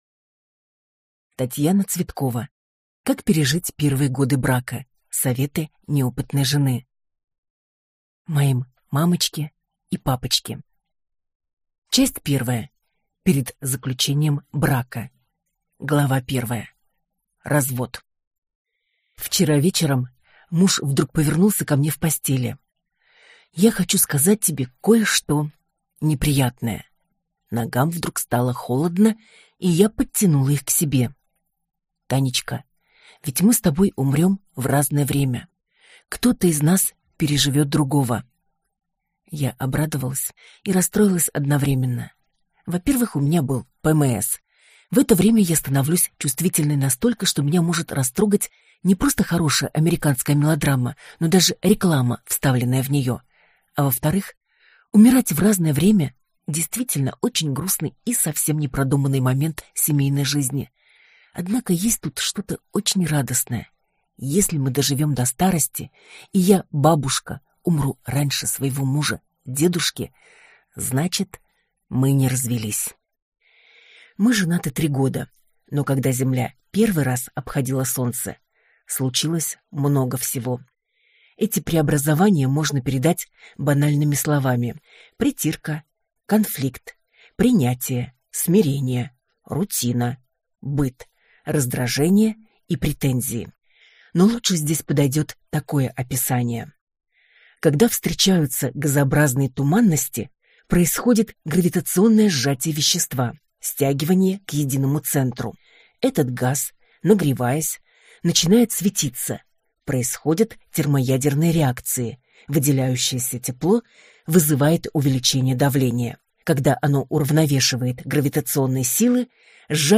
Аудиокнига Как пережить первые годы брака. Советы неопытной жены | Библиотека аудиокниг